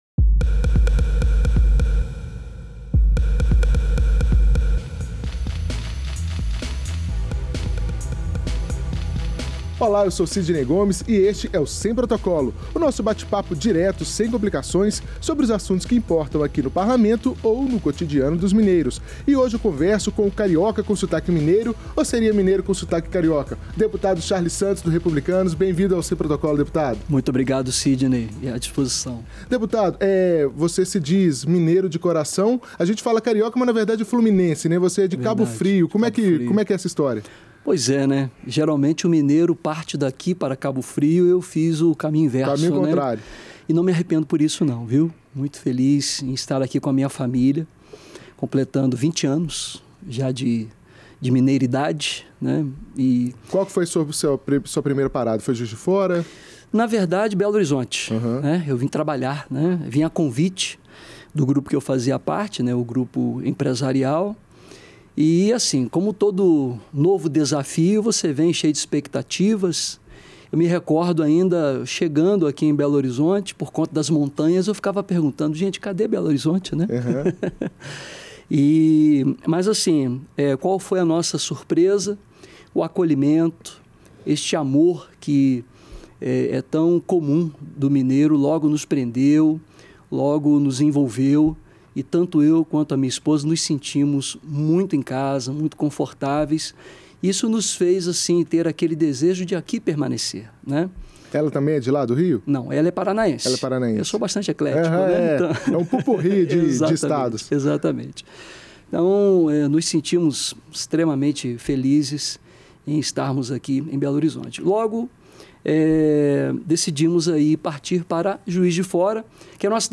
No Setembro Amarelo, mês de prevenção ao suicídio, o parlamentar dá um testemunho de quem conviveu por 30 anos com a depressão.